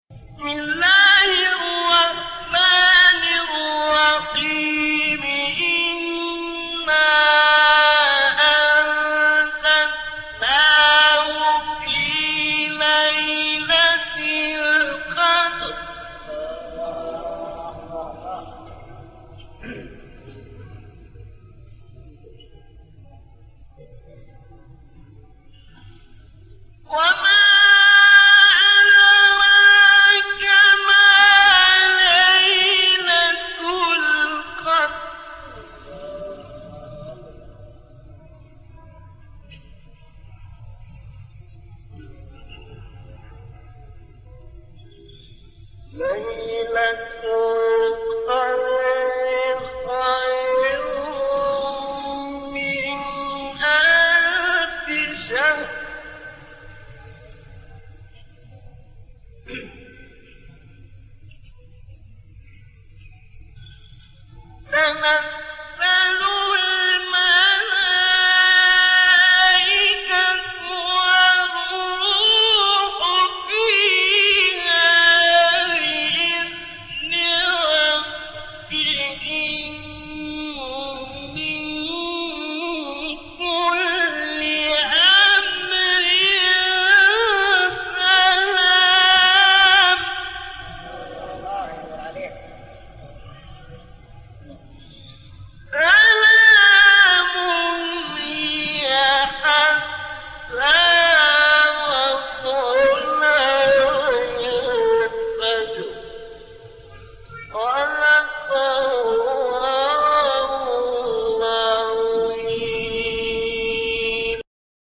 گروه شبکه اجتماعی: فرازهایی از تلاوت قاریان ممتاز مصری در زیر ارائه می‌شود.